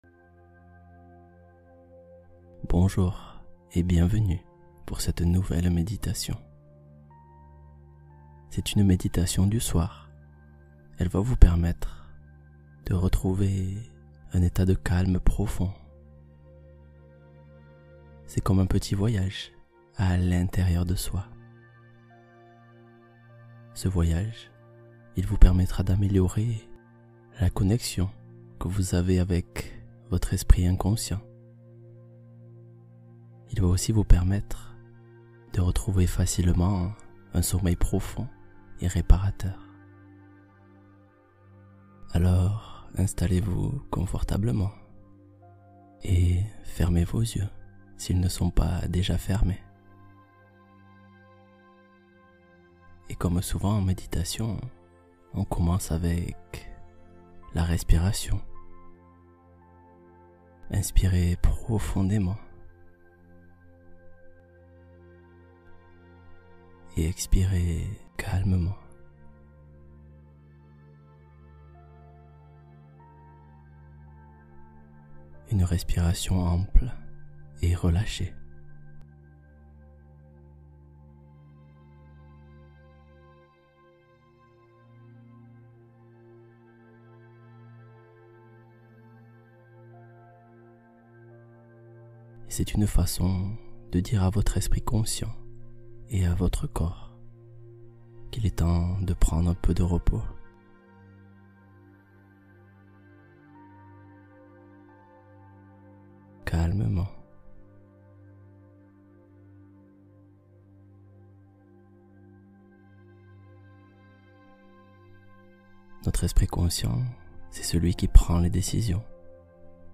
Méditation pour dormir : traverser le tableau vers la douceur de la nuit